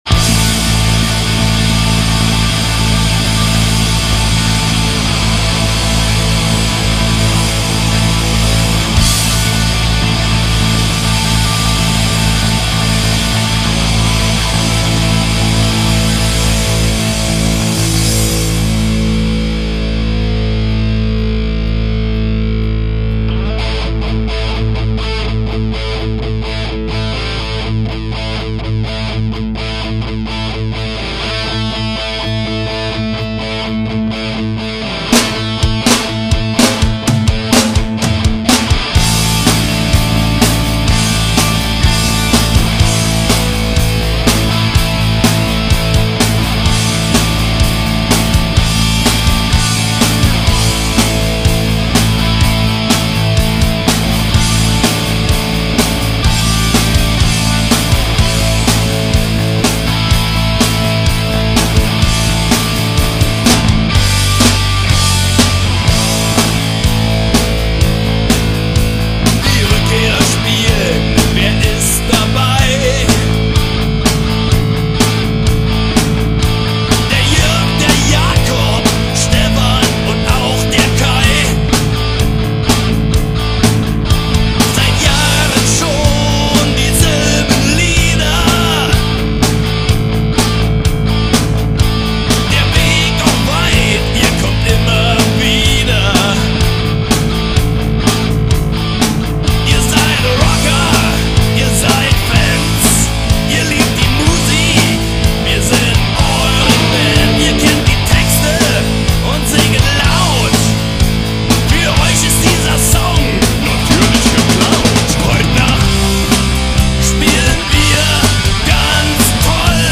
Heavy Metal
Der Sound übertrifft natürlich alles bisherige.